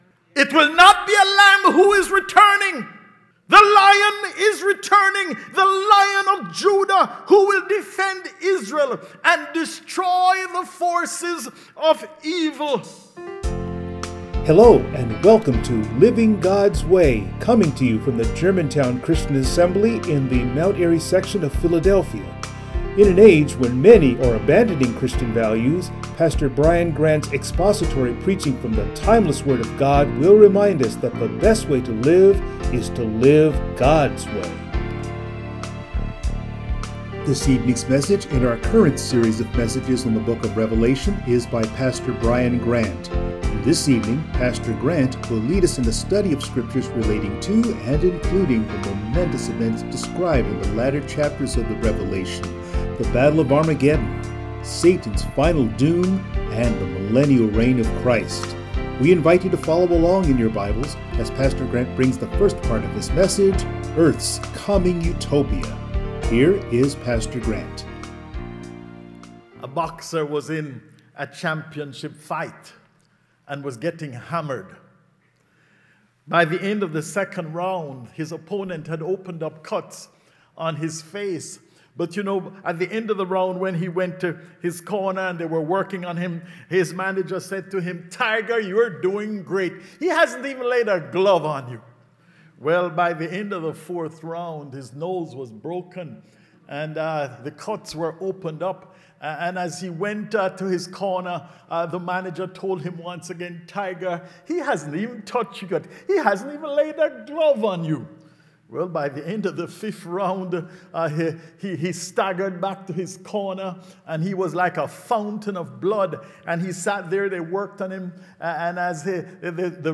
Passage: Revelation 19:11-21 Service Type: Sunday Morning